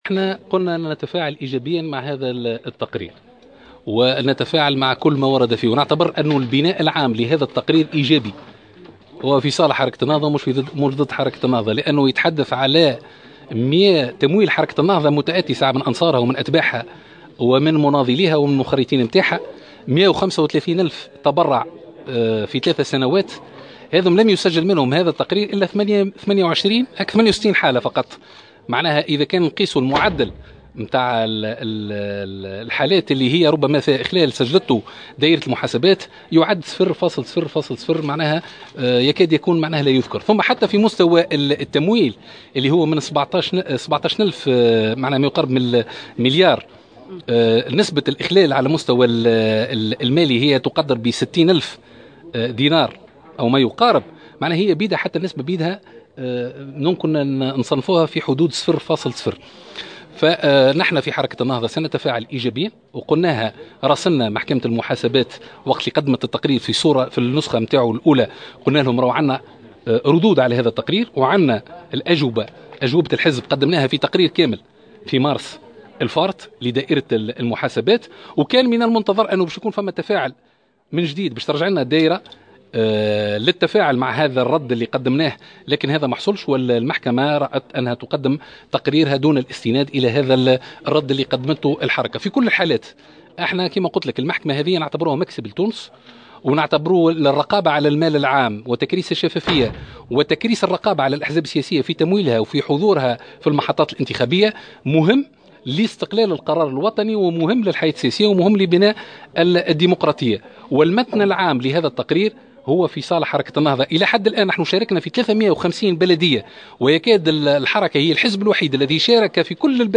وأضاف في تصريح لمراسلة "الجوهرة أف أم" على هامش ندوة عقدتها الحركة بتونس العاصمة، أن تقرير دائرة المحاسبات ايجابي ويصب في صالح حركة النهضة حيث أن من بين 135 ألف تبرع خلال ثلاث سنوات لم يتم تسجيل الا 68 حالة اخلال، مشيرا الى أن معدل الاخلالات ضعيف جدا.